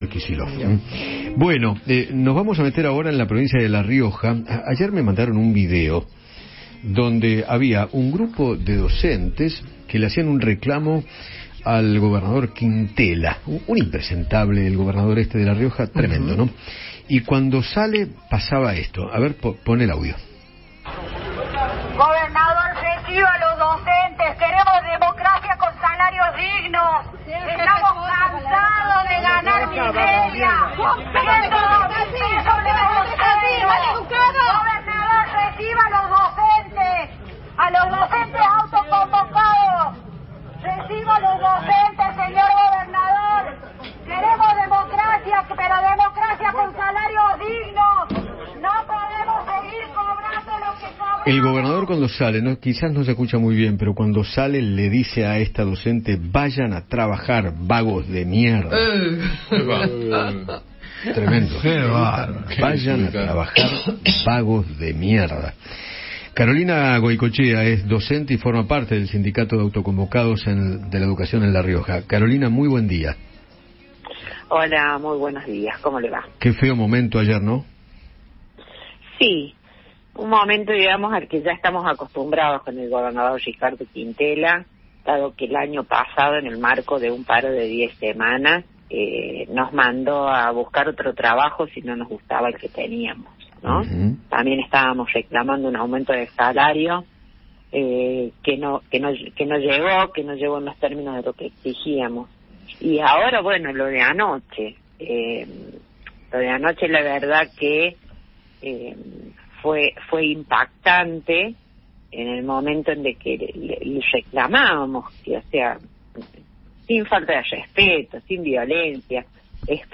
dialogó con Eduardo Feinmann sobre la respuesta del gobernador Roberto Quintela ante el reclamo que están llevando adelante.